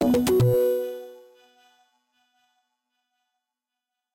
sfx_transition-03.ogg